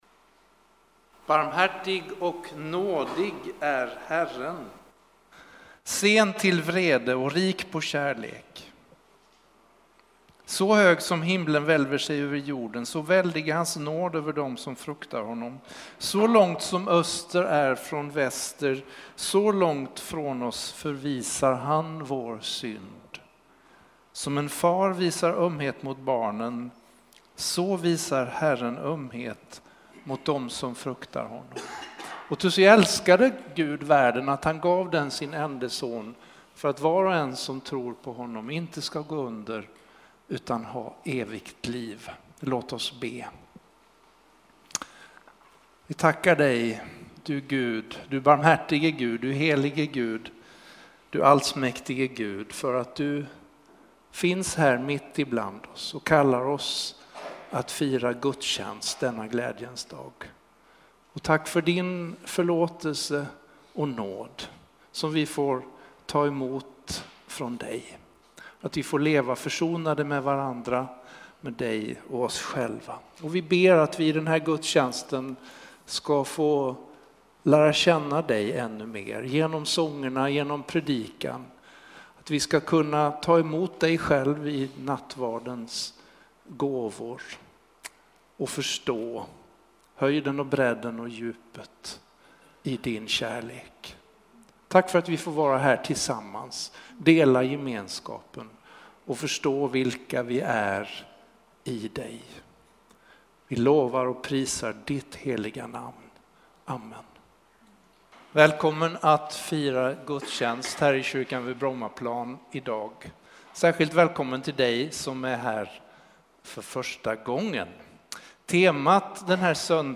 Textläsning, predikanPsalm 103:8-14, Joh 3